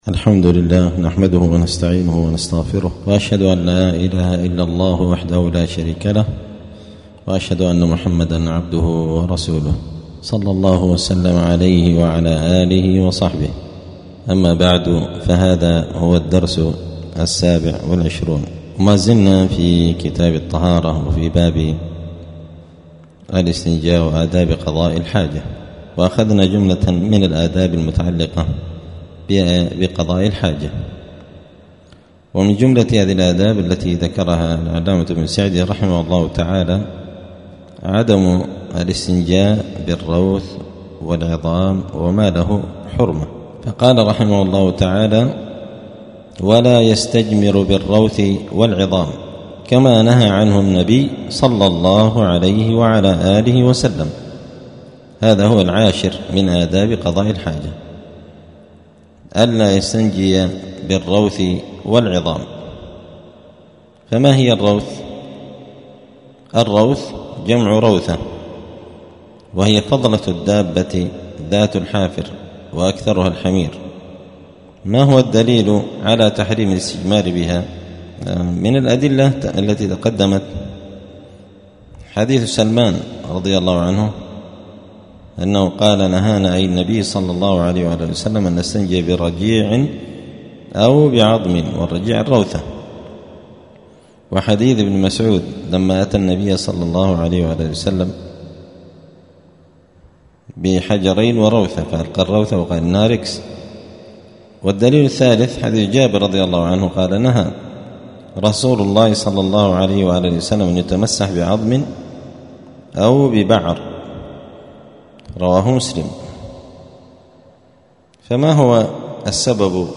*الدرس السابع والعشرون (27) {كتاب الطهارة باب الاستنجاء وآداب قضاء الحاجة ومن الآداب عدم الاستنجاء بالروث والعظام}*